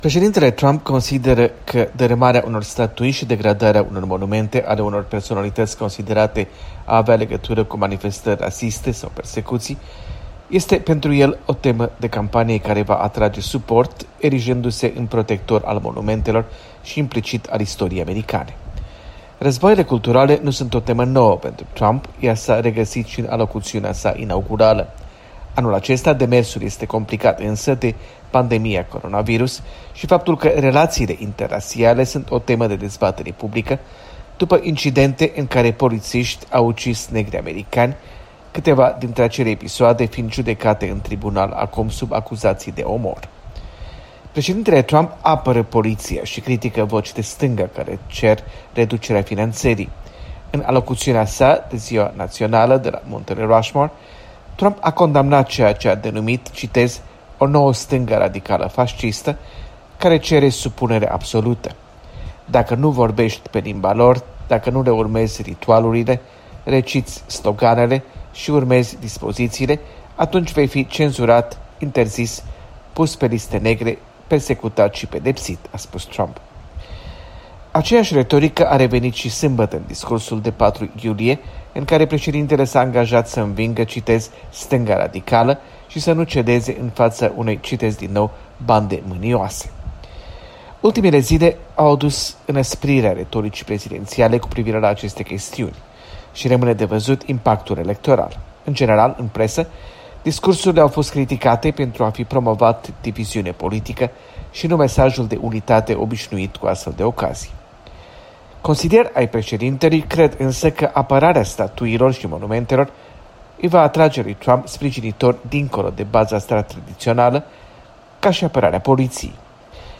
Corespondență de la Washington: războiul culturilor